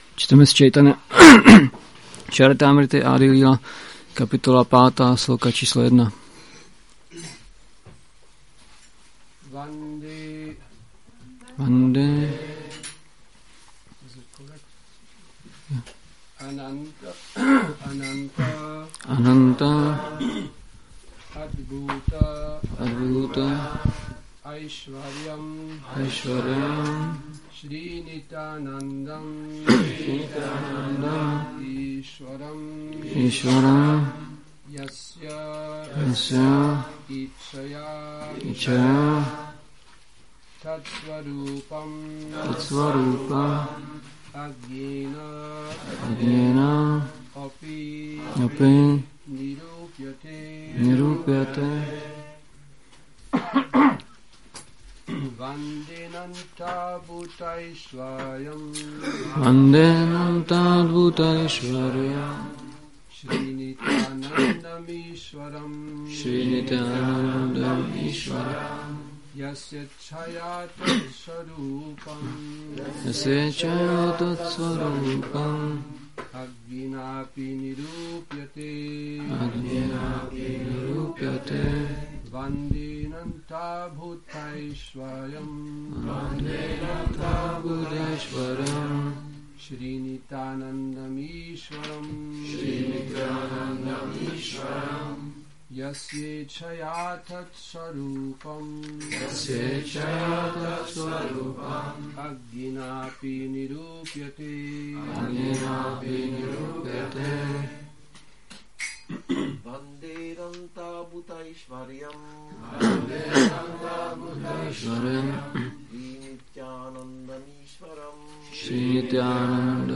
Přednáška CC-ADI-5.1